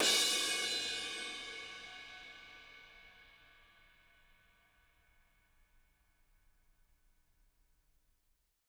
R_B Crash B 01 - Room.wav